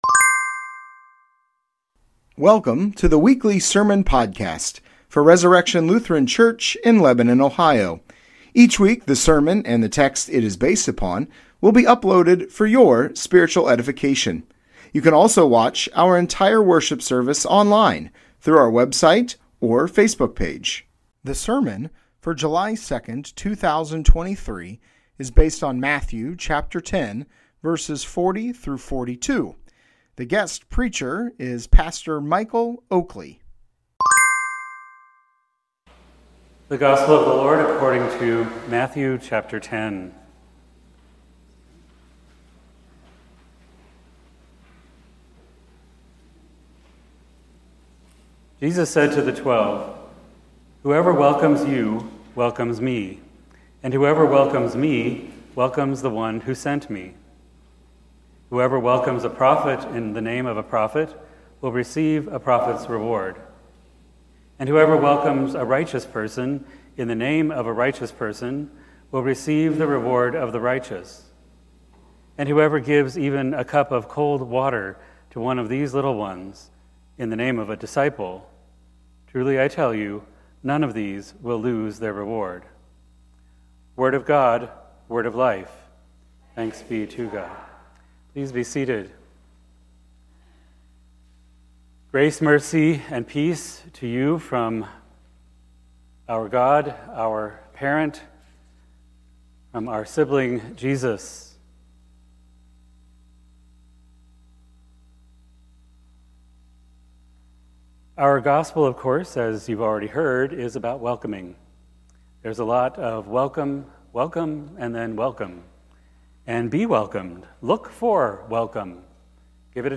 Sermons | Resurrection Lutheran Church